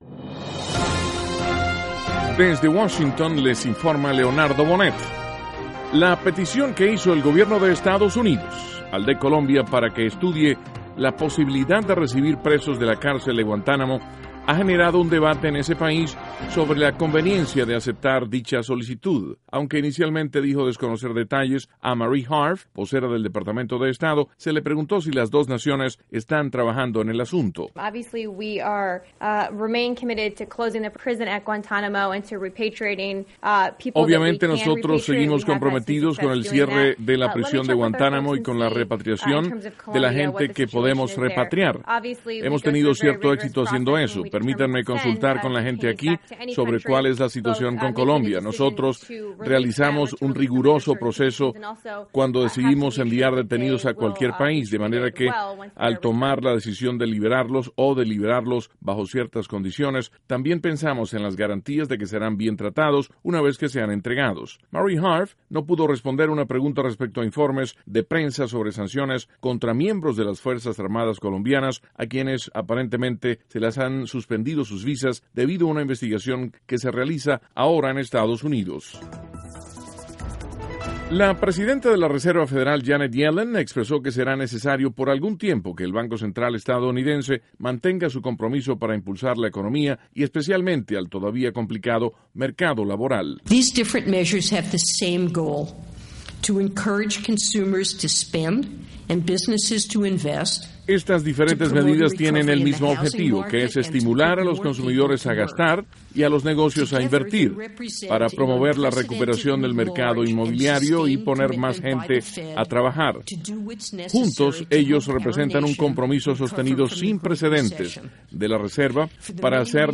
NOTICIAS - LUNES, 31 DE MARZO, 2014
(Sonido – Harf) 2.- Yanet Yellen, presidenta de la Reserva Federal de Estados Unidos, explica las medidas de la entidad para enfrentar la recesión. (Sonido – Yellen) 3.- Lulfthansa, la principal línea aérea de Alemania, cancela miles de vuelos por huelga de pilotos.